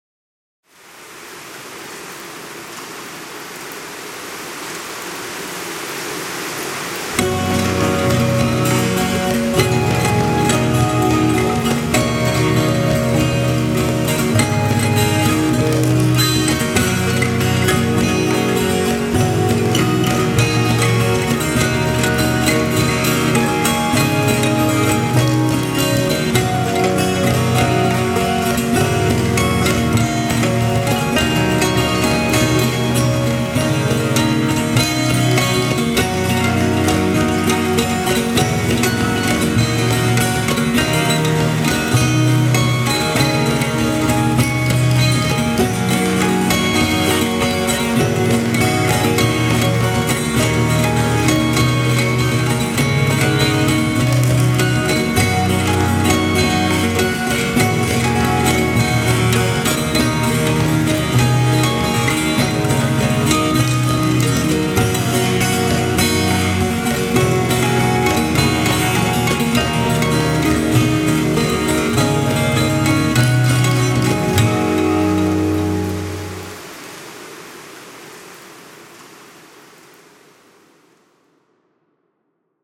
エスニック
アコギ
癒し